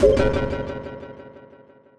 blue laser.wav